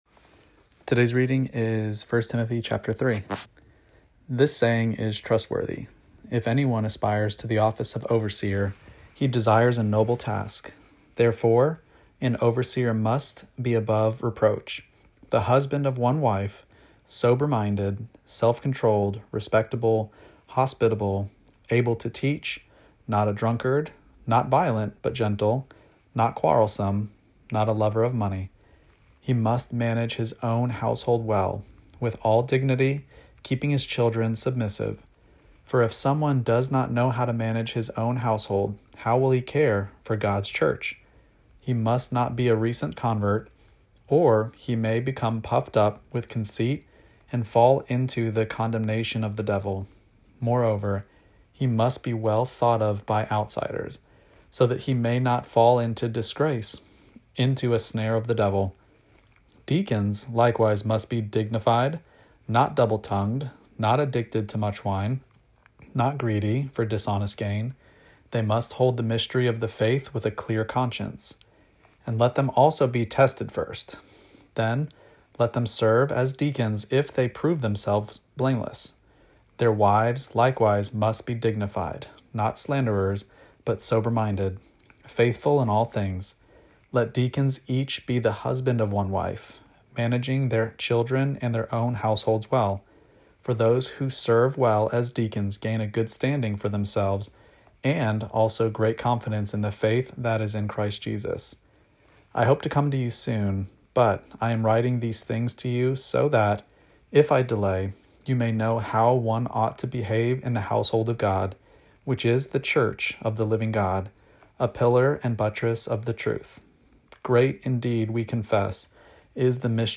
Daily Bible Reading (ESV) October 23: 1 Timothy 3 Play Episode Pause Episode Mute/Unmute Episode Rewind 10 Seconds 1x Fast Forward 30 seconds 00:00 / 2:14 Subscribe Share Apple Podcasts Spotify RSS Feed Share Link Embed